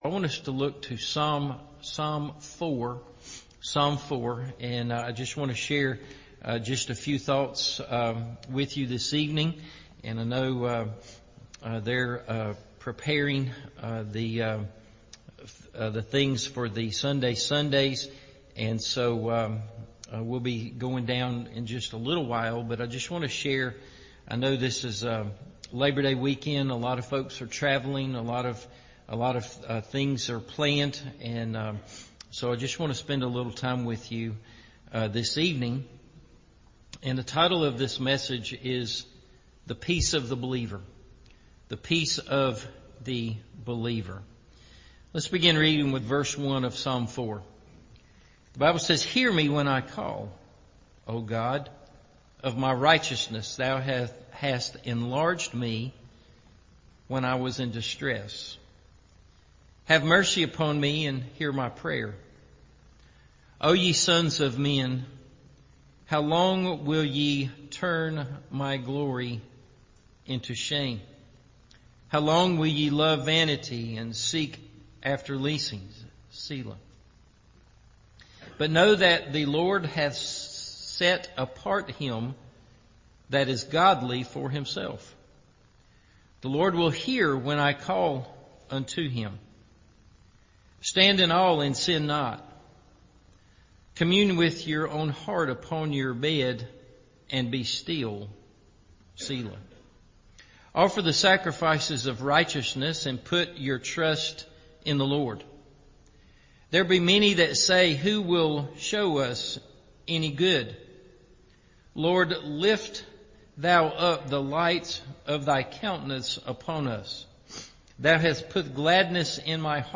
The Peace Of The Believer – Evening Service